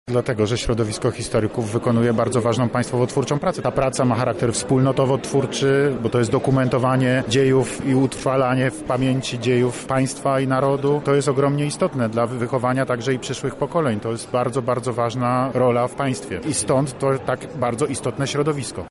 O to, dlaczego wydarzenie je otrzymało, nasza reporterka zapytała Prezydenta Polski Andrzeja Dudę: